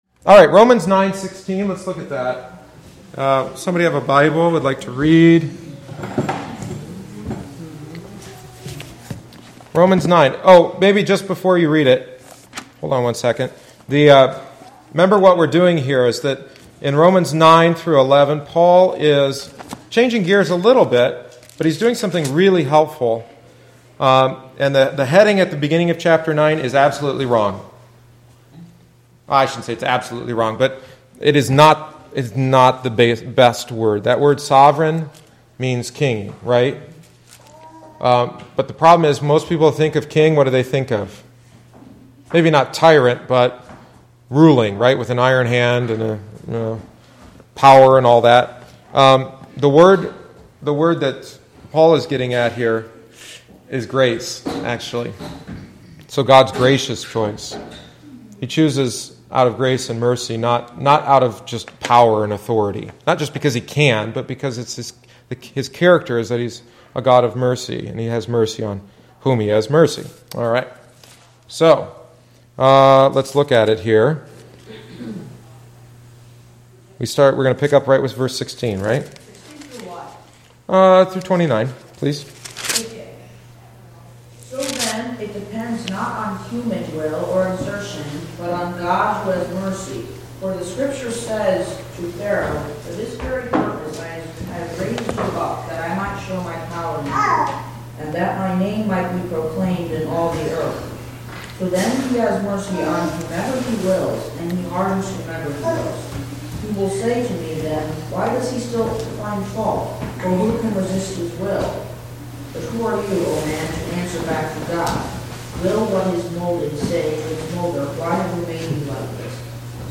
The following is the twenty-third week’s lesson. God’s elect are saved; those who reject God’s grace are condemned.